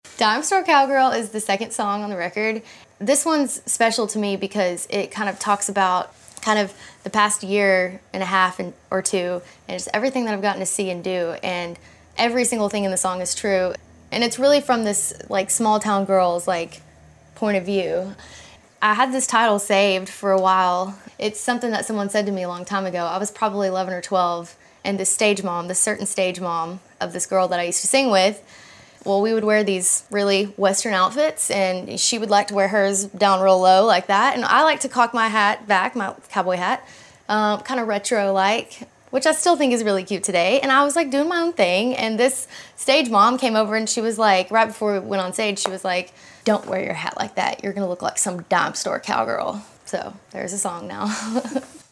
Audio / Kacey Musgraves explains how she came up with the song “Dime Store Cowgirl,” which is featured on her new album, Pageant Material, out June 23rd.
[laughs] :52